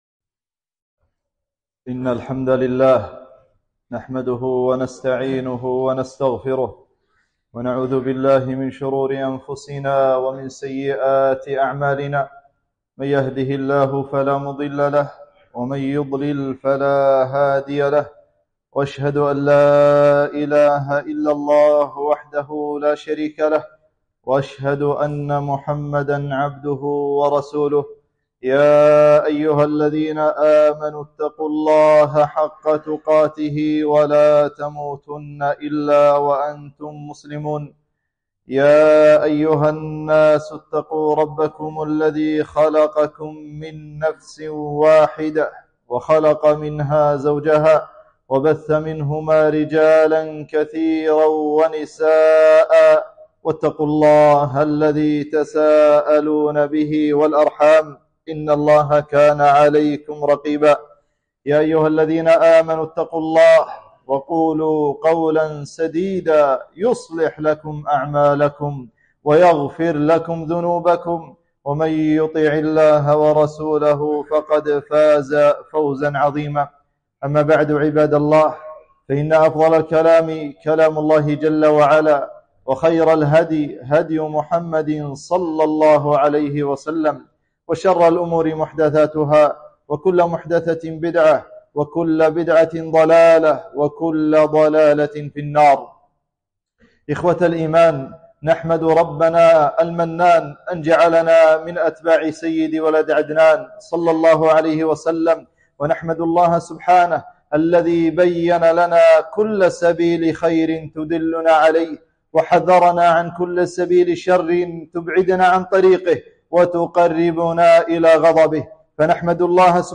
خطبة - حقارة الدنيا